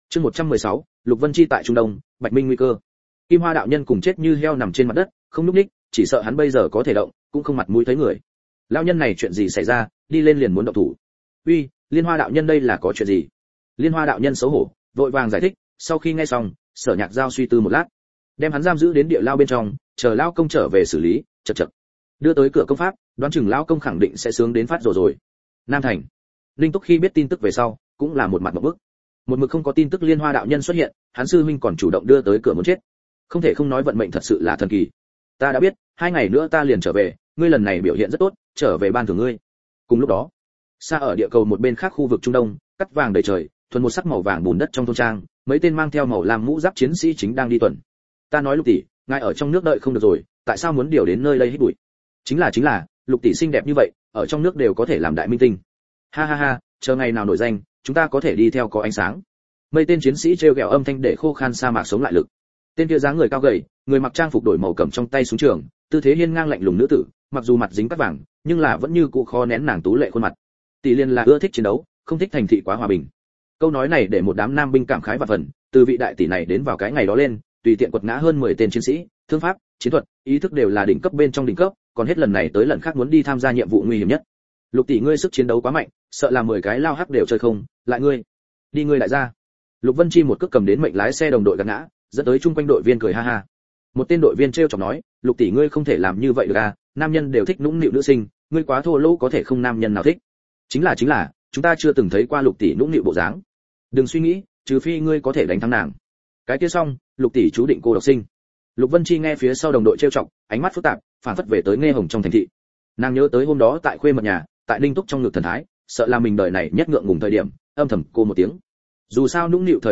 Tu Tiên Giới Quá Nguy Hiểm, Ta Cẩu Ở Địa Cầu Hiển Thánh Audio - Nghe đọc Truyện Audio Online Hay Trên AUDIO TRUYỆN FULL